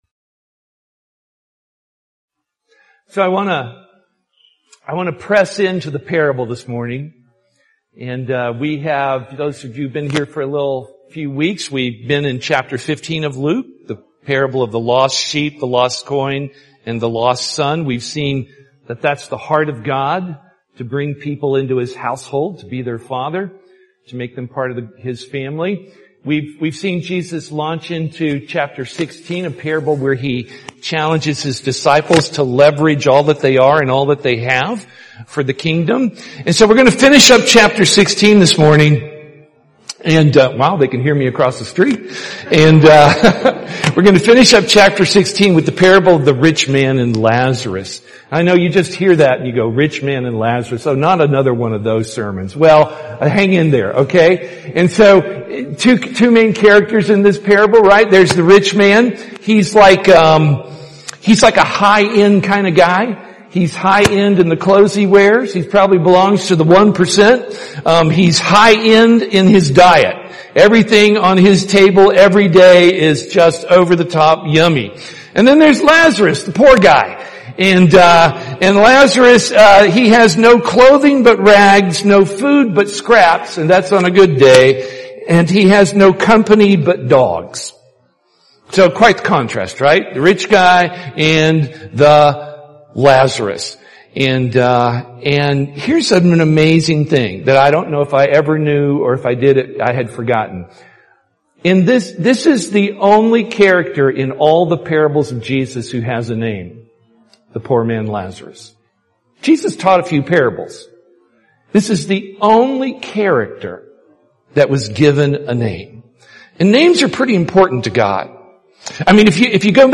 Posted on Oct 4, 2022 in Sermons, Worship |